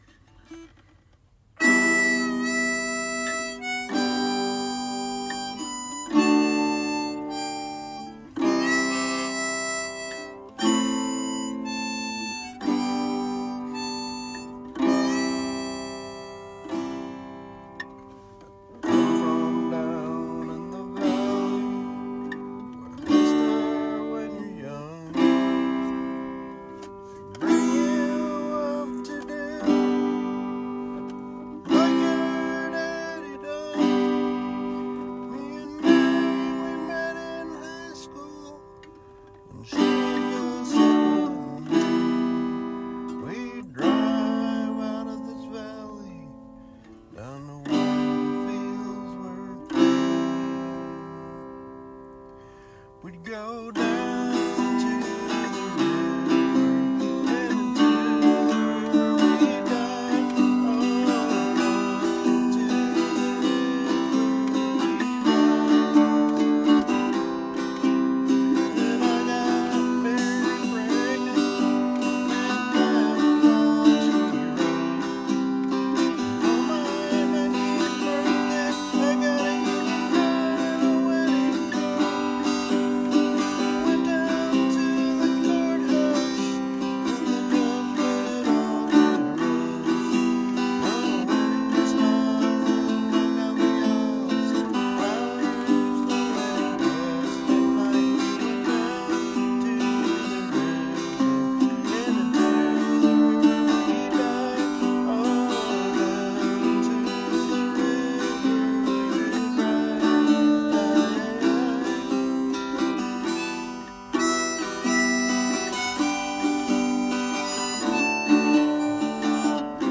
LOCATION: Back seat of my car @ local park
Guitar: Martin Backpacker Steel String
Harmonica
Recording: Work Phone
my work phone.  Very rough.